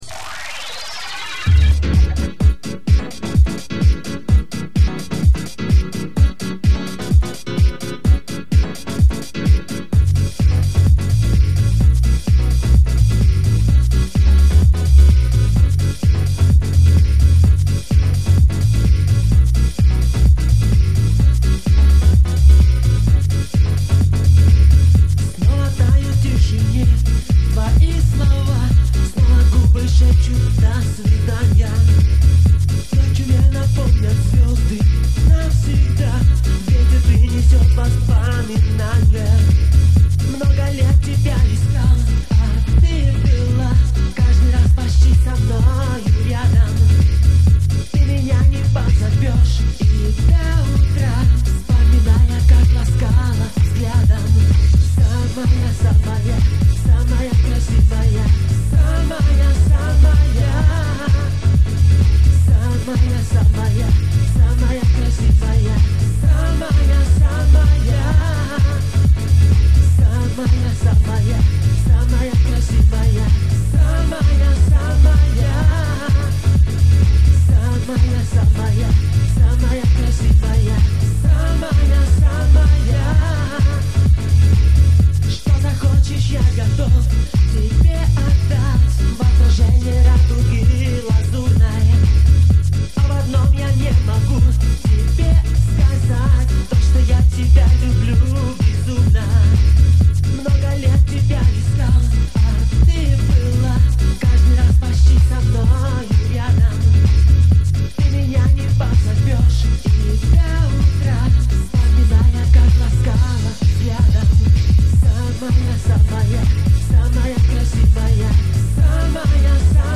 инструментальная пьеса
синтезатор.